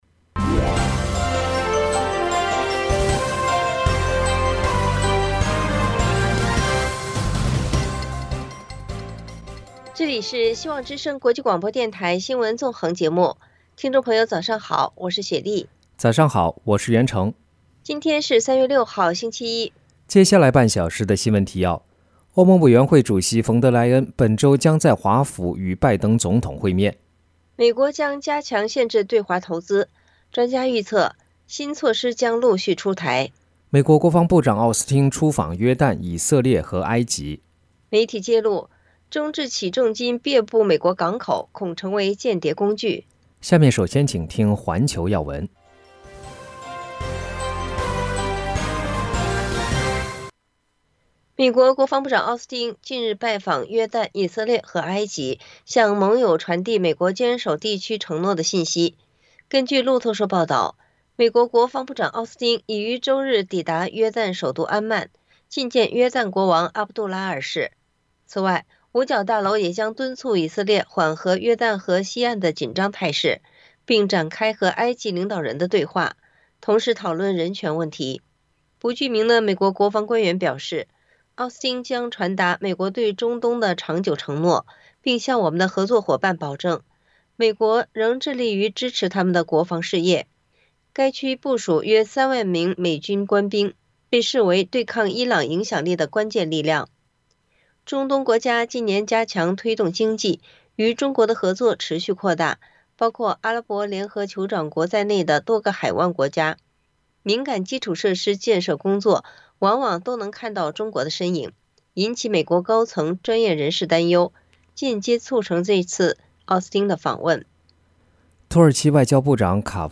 中共军费成长比GDP高 学者：强军队优于强民生【晨间新闻】